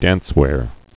(dănswâr)